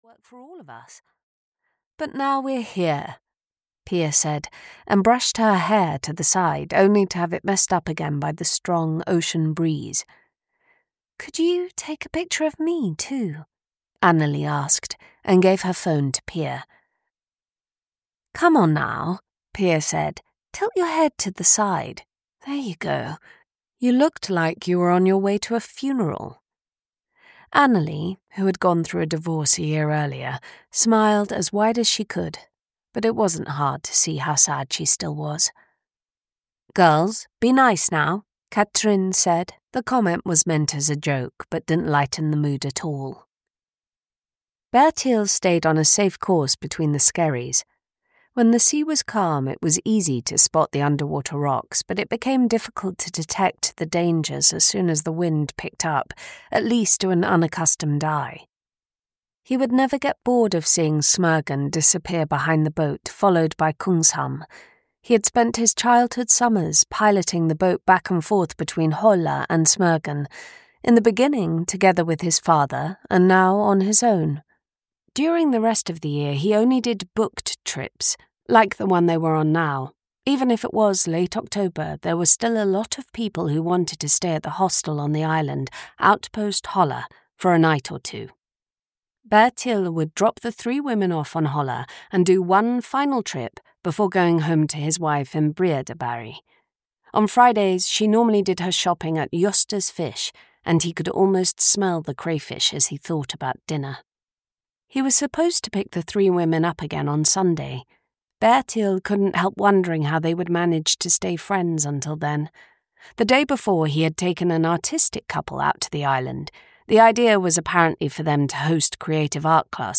The Lighthouse Keeper (EN) audiokniha
Ukázka z knihy